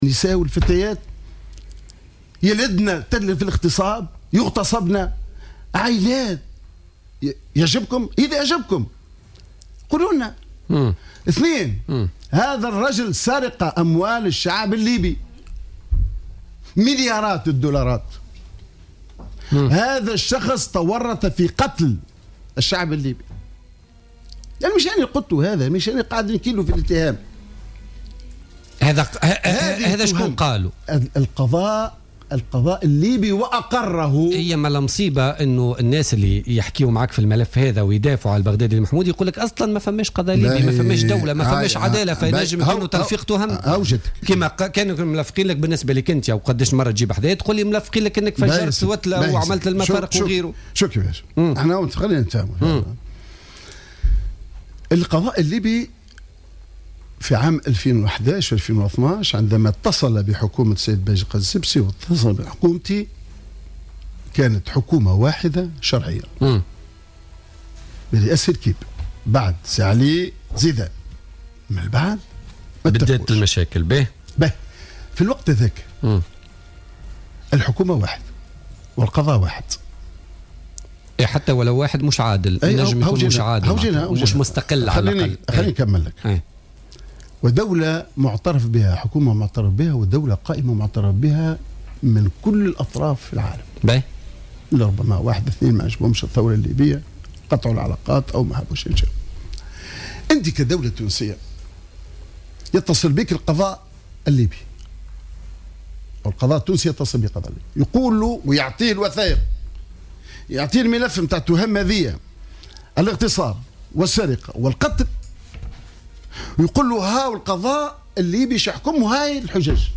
قال رئيس الحكومة السابق،حمادي الجبالي ضيف برنامج "بوليتيكا" إن رئيس الوزراء الليبي السابق البغدادي المحمودي ليس لاجئا سياسيا وإنه تورط في قتل الشعب الليبي و نهب أمواله وكذلك في قضايا اغتصاب.